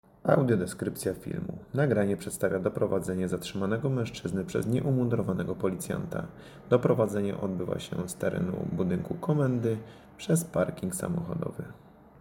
Nagranie audio Audiodeskrypcja.mp3